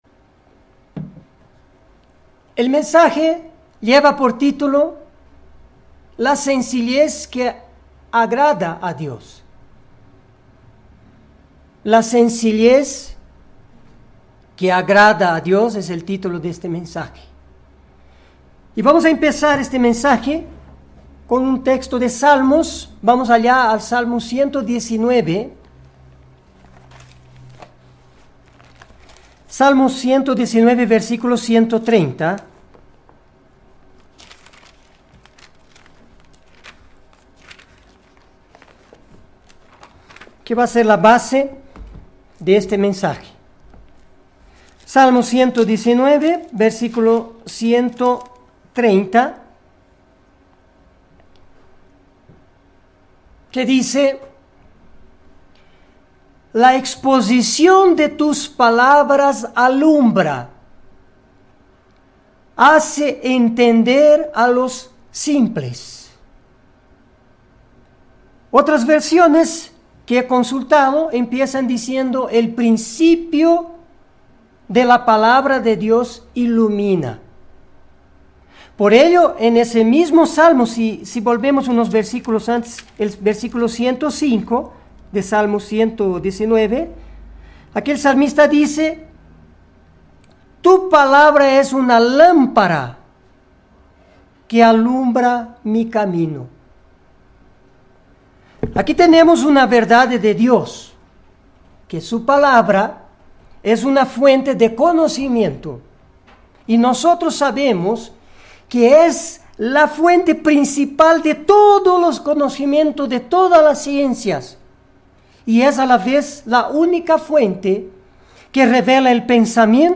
Mensaje entregado el 22 de junio de 2019.